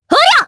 Sonia-Vox_Attack3_jp.wav